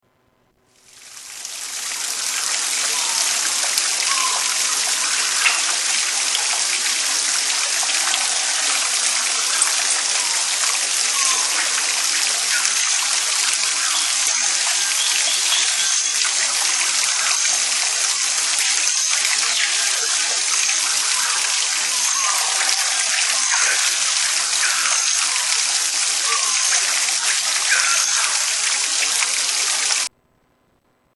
Rainforest Ambience 7
Category: Animals/Nature   Right: Personal